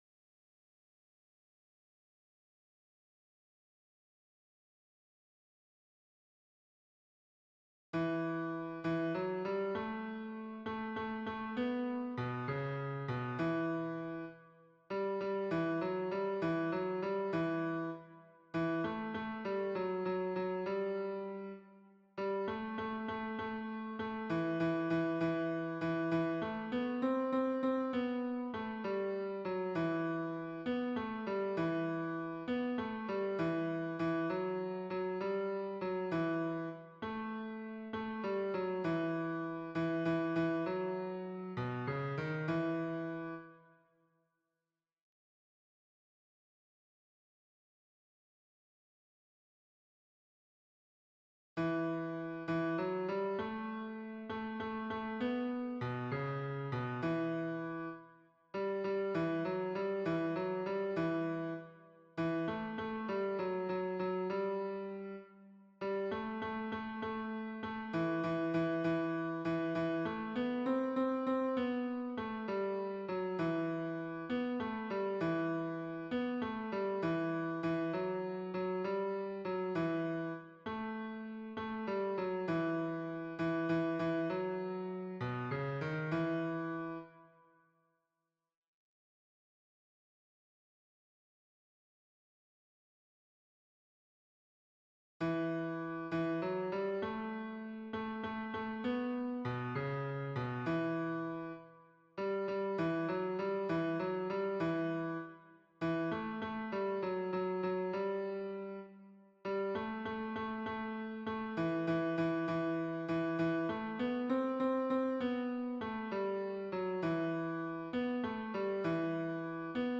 MP3 version piano
Hommes